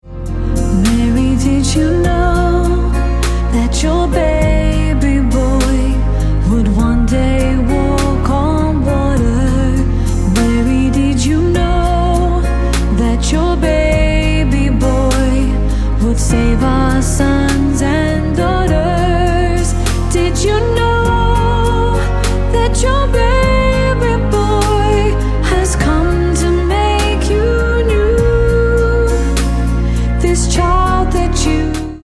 Bbm